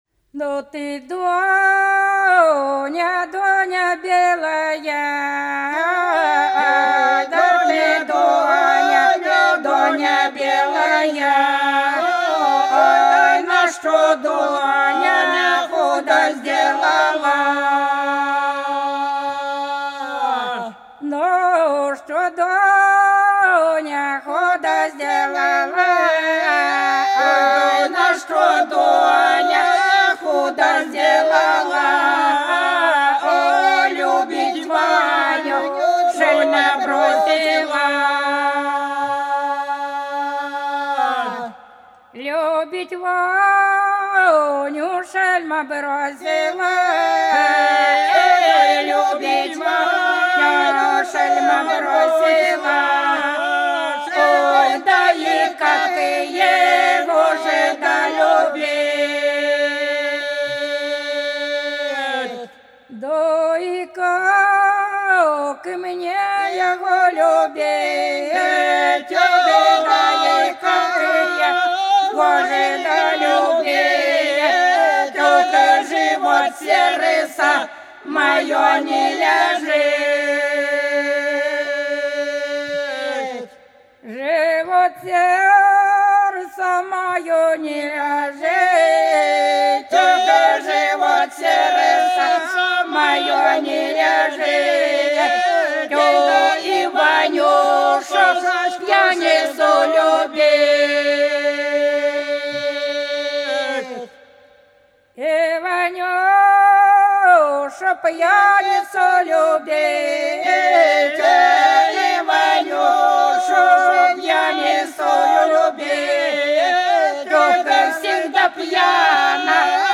По-над садом, садом дорожка лежала Да Доня, Доня белая - постовая (с.Плёхово, Курская область)